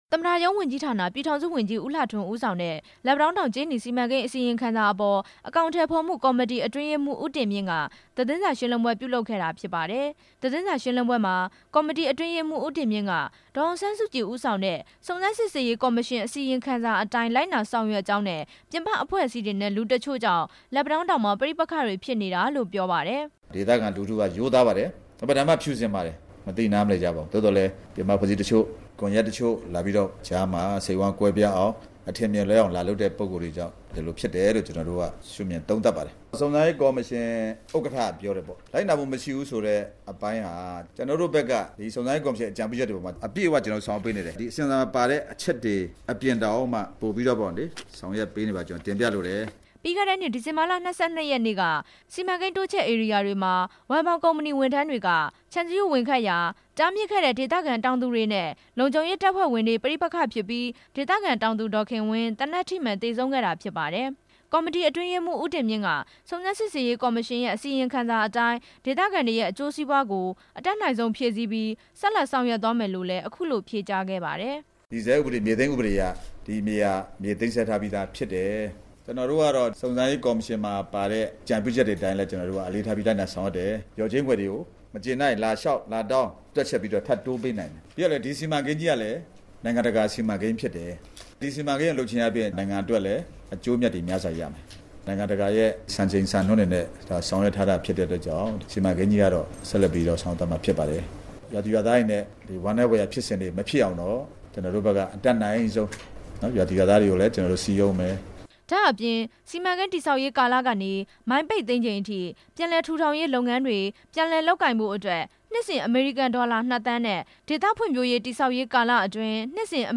သတင်းစာရှင်းလင်းပွဲအကြောင်း တင်ပြချက်